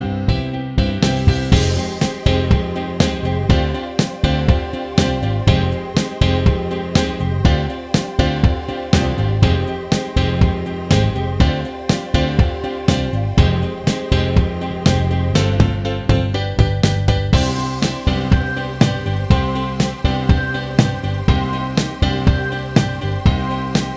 Minus All Guitars Pop (1980s) 4:19 Buy £1.50